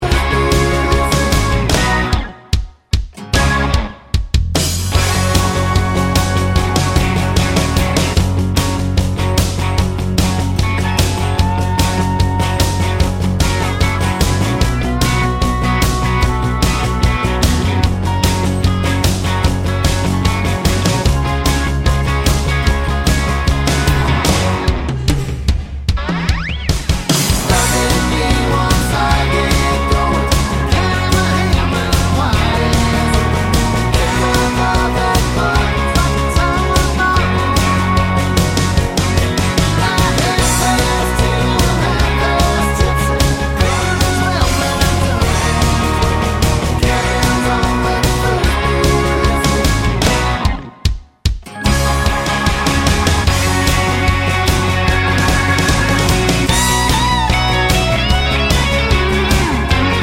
no Backing Vocals Country (Male) 3:00 Buy £1.50